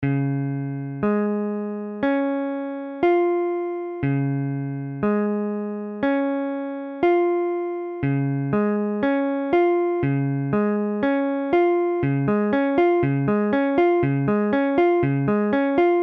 Illustration sonore : IV_Db.mp3